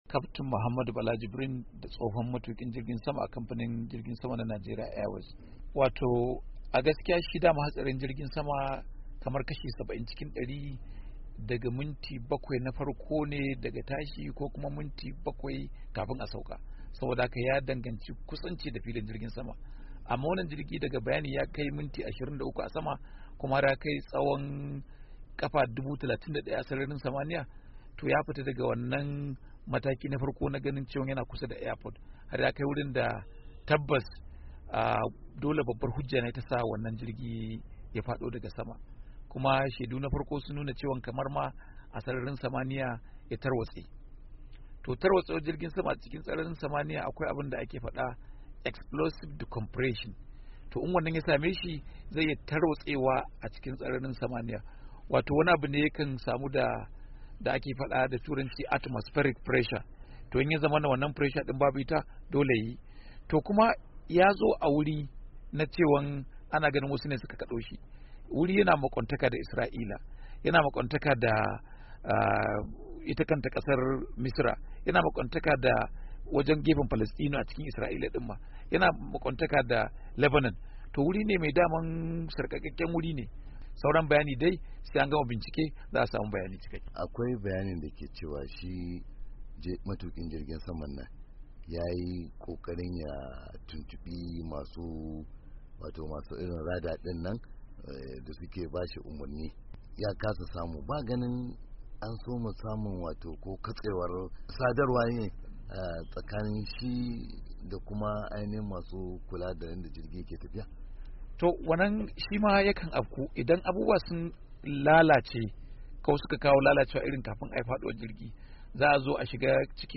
Yayinda kwararru daga kasashen Rasha da kuma Misira suke gudanar da bincike domin gano masababin faduwar jirgin saman kasar Rasha ranar asabar da ya yi sanadin rasa rayukan sama da mutane dari biyu, Wani tsohon matukin jirgin sama a Najeriya ya yi tsokaci kan abubuwan dake haddasa haduran jirgi.